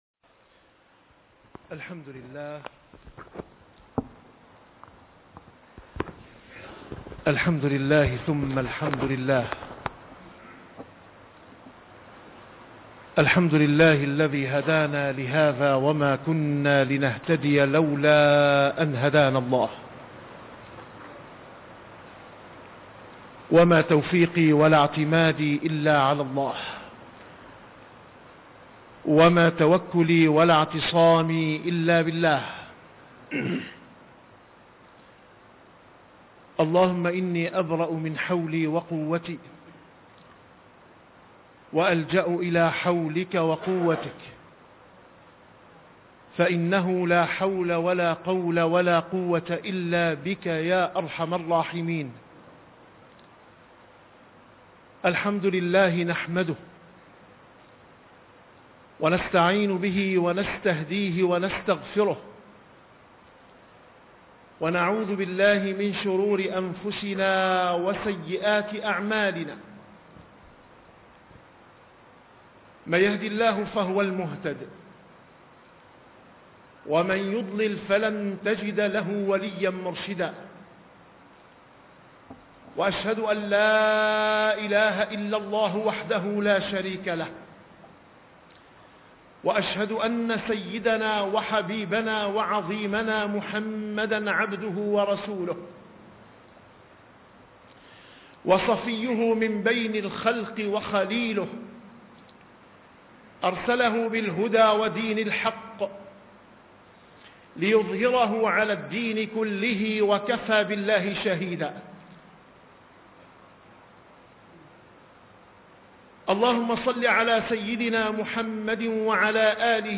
- الخطب - من ثمرات الحب الخالص: الصلاة بالليل